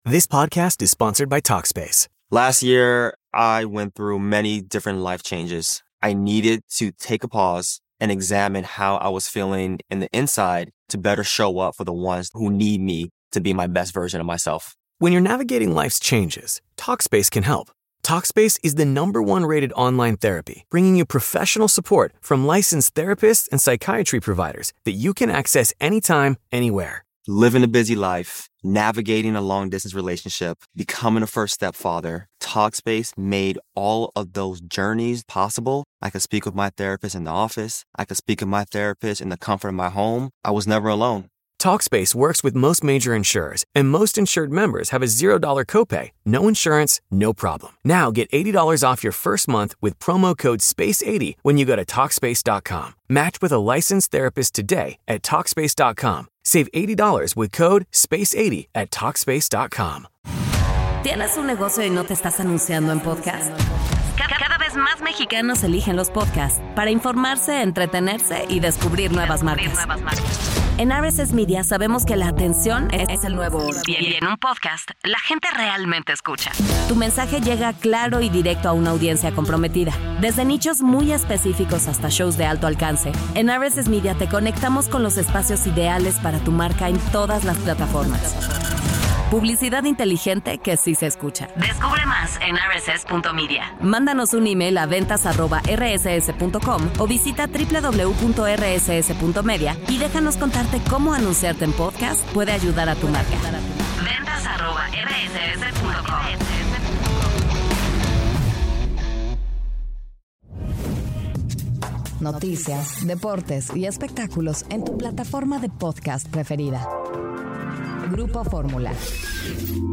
Aquí está la Radio de la República, el peor noticiero con su tuitero favorito.